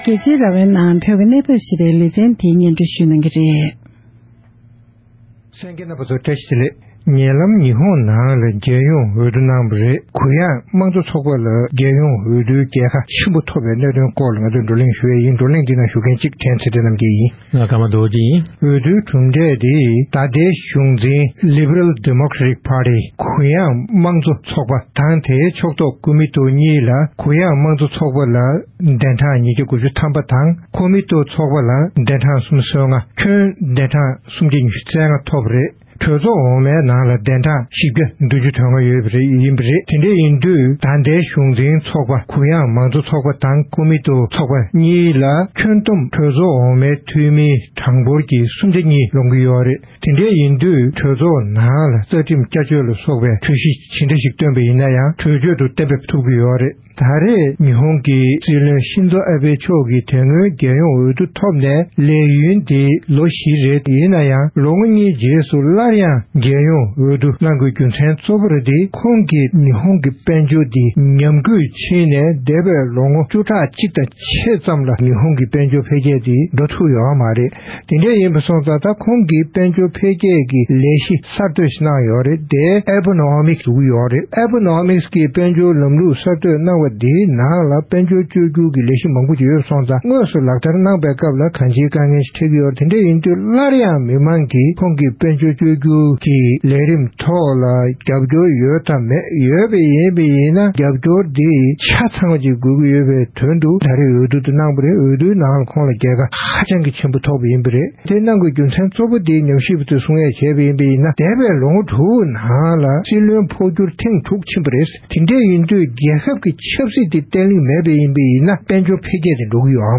ཉེ་ཆར་ཉི་ཧོང་རྒྱལ་ཡོངས་འོས་བསྡུའི་ནང་གྲུབ་འབྲས་ཐོག་དཔྱད་གླེང་གནང་བ།